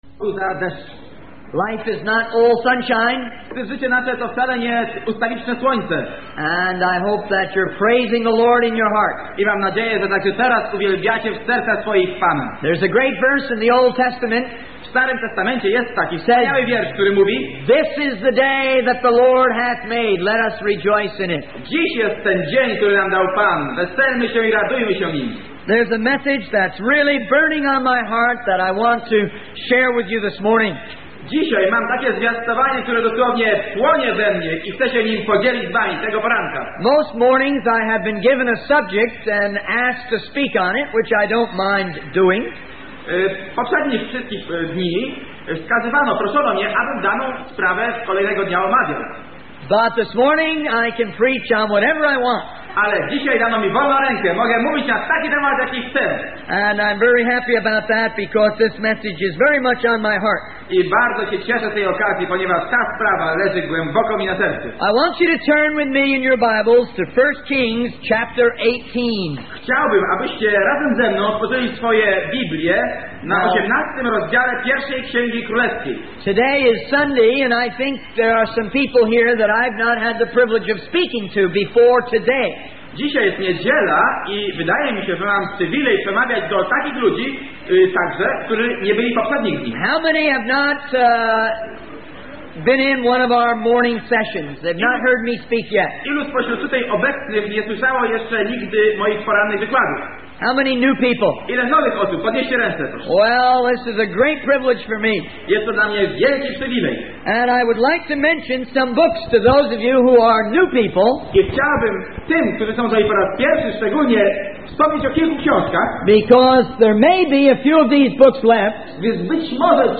In this sermon, the preacher emphasizes the reality of the fire of God in the believer's life. He shares a personal experience of burning his hand to illustrate the tangible nature of fire.